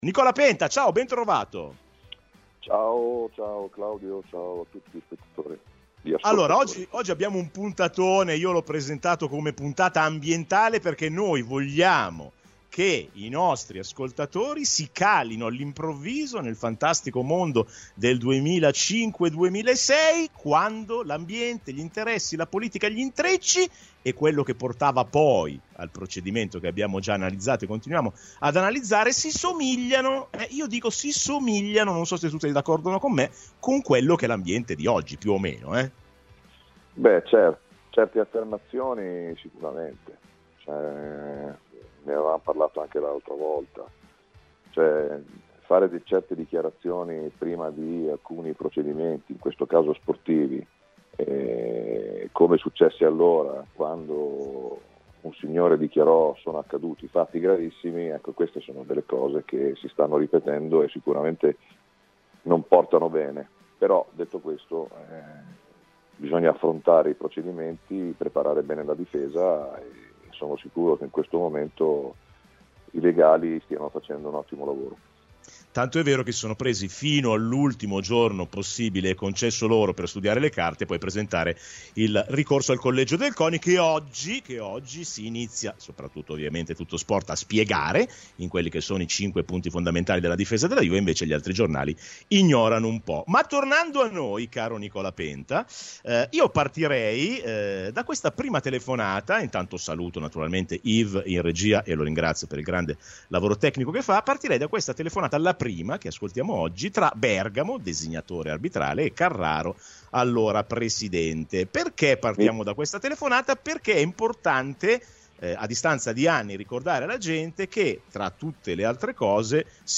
Nel corso della puntata ascolterete tre telefonate: nella prima il designatore Bergamo parla con il Presidente della FIGC Carraro di Chievo-Fiorentina e si può ascoltare Carraro assicurarsi che Dondarini non danneggi la Fiorentina nell'occasione.